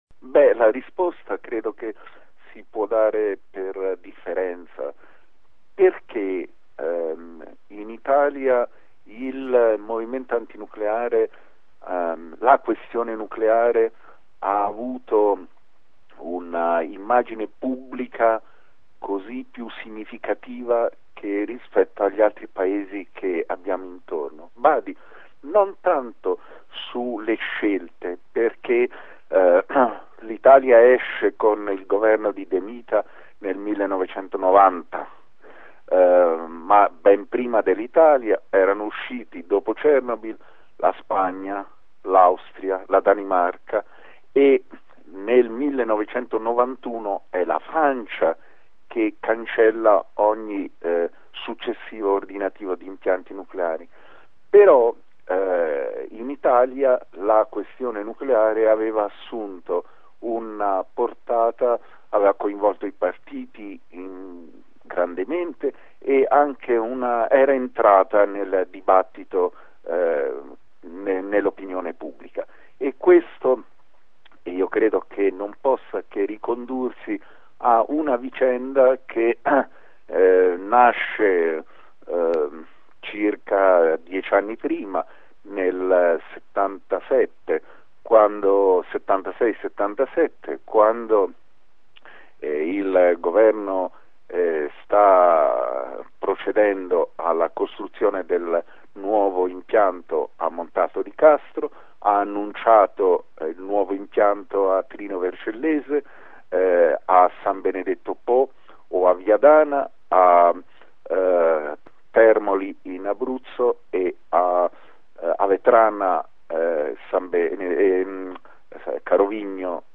Per spiegare a chi non c’era cosa sia stato il movimento anti-nucleare italiano abbiamo intervistato Gianni Mattioli, docente di fisica all’università La Sapienza di Roma, ex ministro dei Lavori pubblici nel governo guidato da Giuliano Amato, ma soprattutto tra le principali voci del movimento antinucleare italiano.